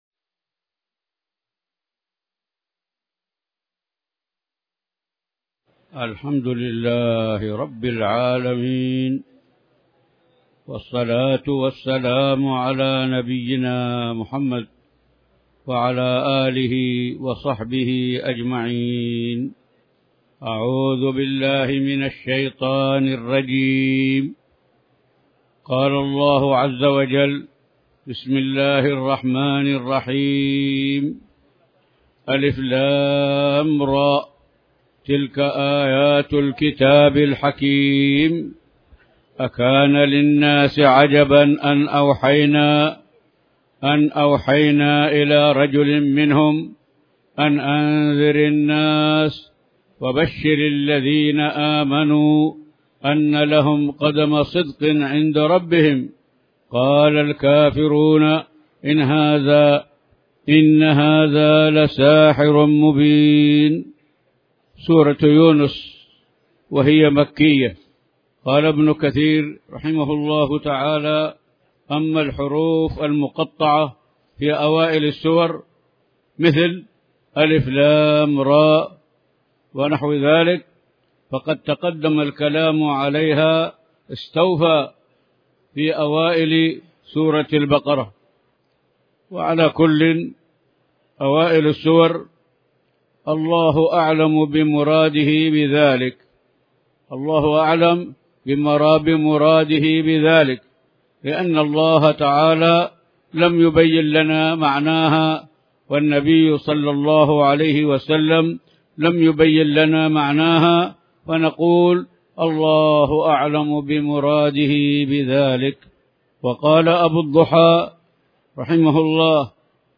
تاريخ النشر ٢٠ ذو الحجة ١٤٣٩ هـ المكان: المسجد الحرام الشيخ